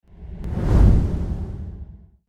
دانلود آهنگ باد 39 از افکت صوتی طبیعت و محیط
دانلود صدای باد 39 از ساعد نیوز با لینک مستقیم و کیفیت بالا
جلوه های صوتی